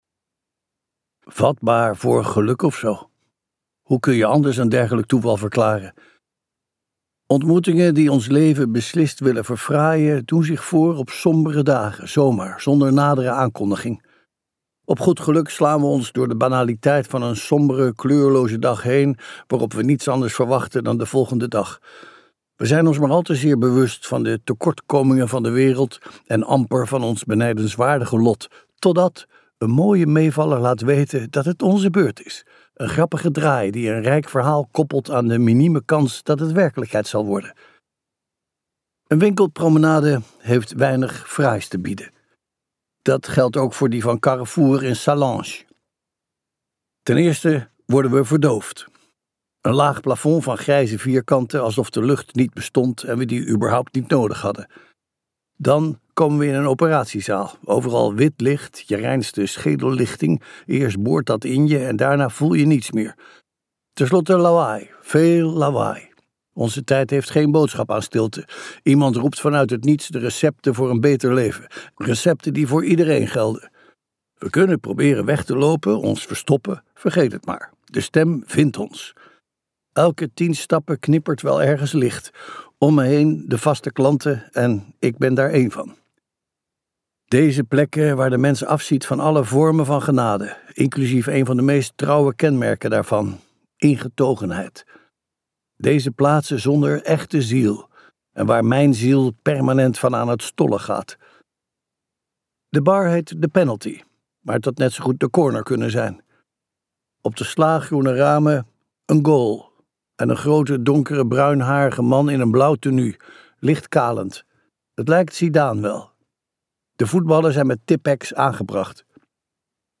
Ambo|Anthos uitgevers - Zijn geur na de regen luisterboek